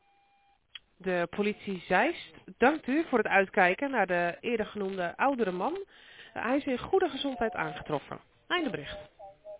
Burgernet heeft een audioboodschap ingesproken bij deze melding.